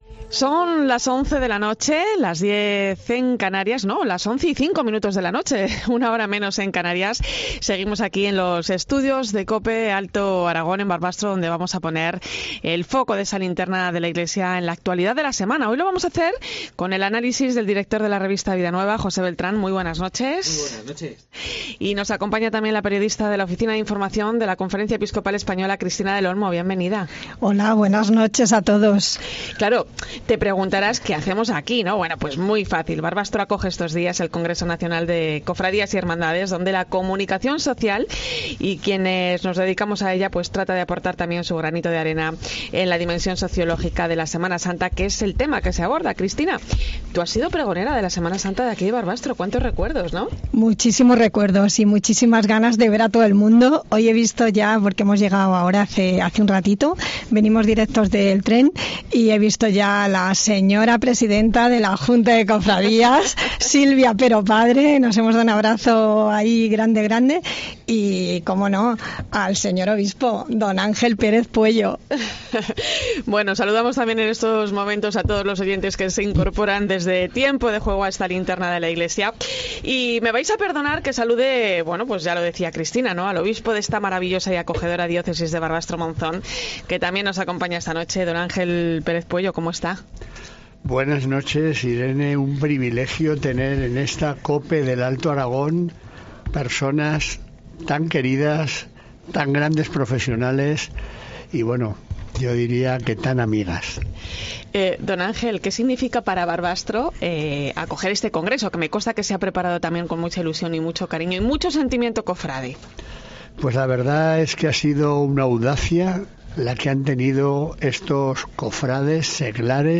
Esta semana hemos encendido 'La Linterna de la Iglesia' desde Barbastro, la capital del Somontano que acoge el Congreso Nacional de Cofradías y Hermandades . Y, por eso, hemos contado con un invitado de excepción en el tiempo del análisis: monseñor Ángel Pérez Pueyo, obispo de Barbastro-Monzón y miembro de la Subcomisión Episcopal para la Familia y la Defensa de la Vida de la Conferencia Episcopal Española.